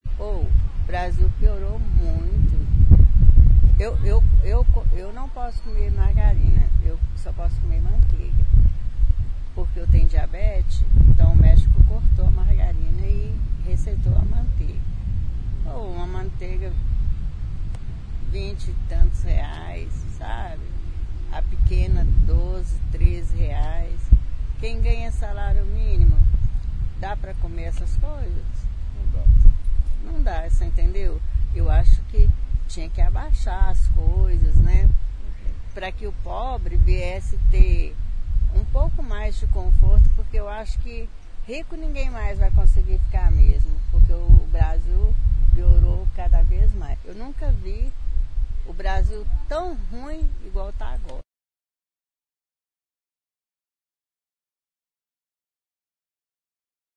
Depoimento